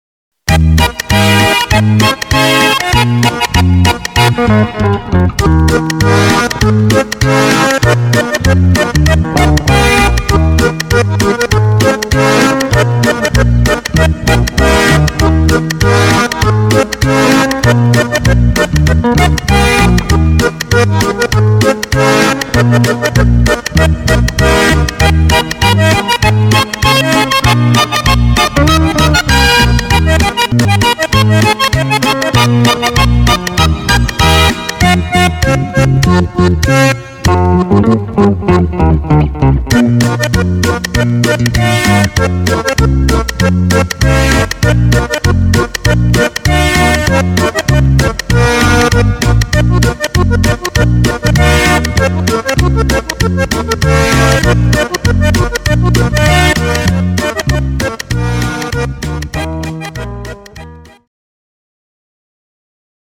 Cued Sample